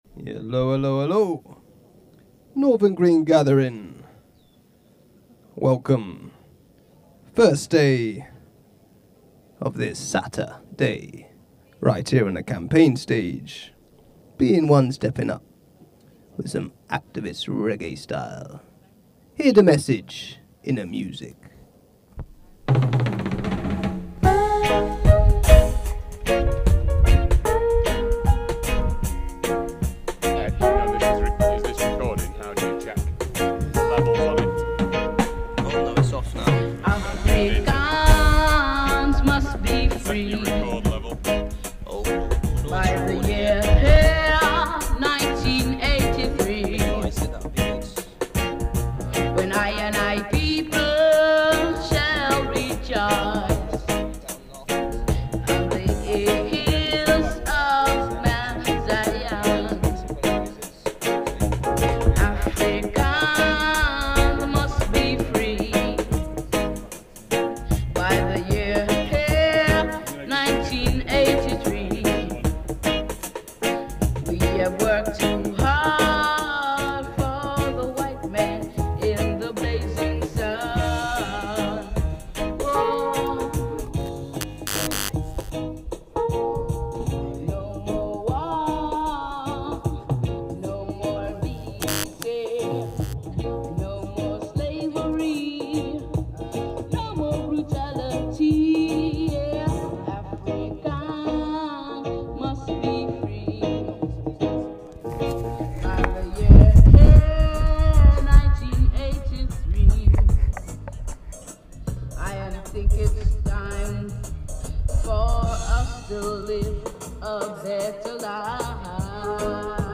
to deliver an activist reggae set